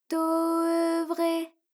ALYS-DB-003-FRA - Source files of ALYS’ first publicly available French vocal library, initially made for Alter/Ego.